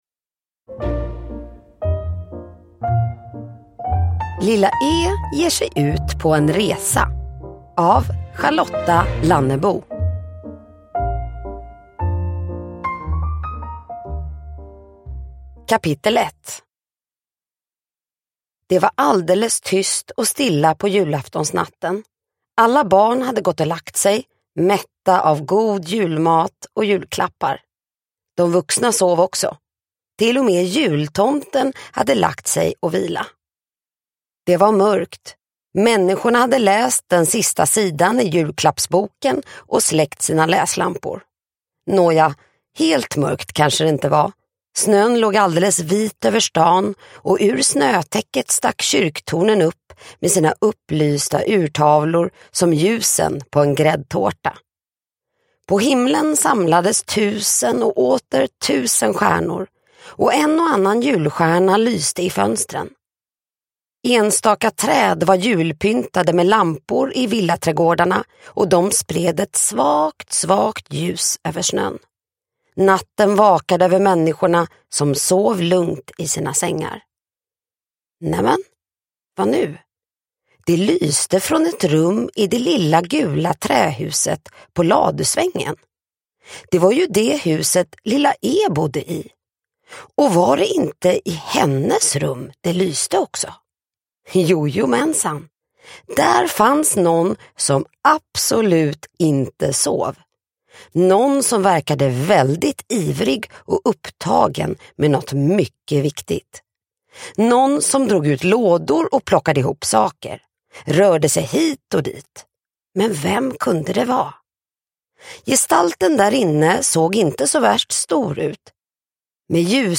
Lilla E ger sig ut på resa – Ljudbok – Laddas ner